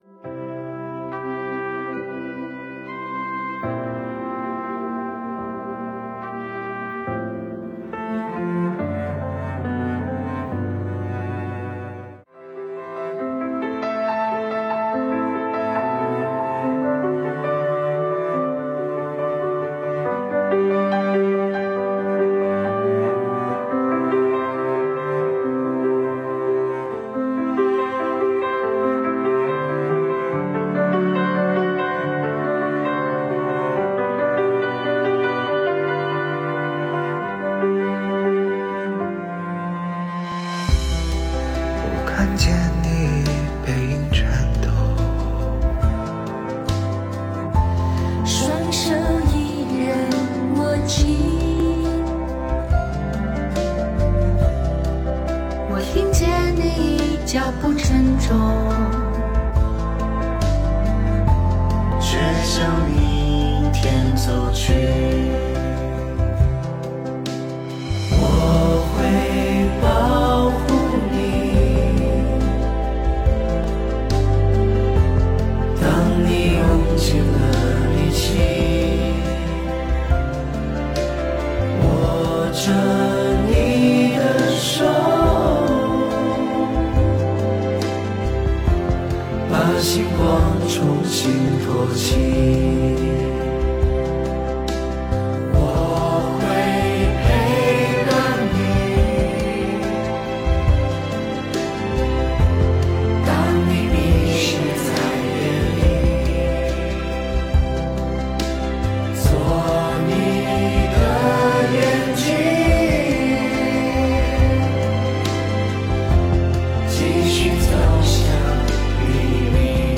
区局六名亲身战“疫”的青年，结合自身经历体会，讲述了长税青年的使命和担当。他们有的深入抗疫一线志愿支援，有的坚守岗位保障服务，他们不是生而英勇而是选择无畏，他们用实际行动诠释了不忘初心的青春本色，彰显了牢记使命的青春担当。